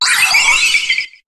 Cri de Créfadet dans Pokémon HOME.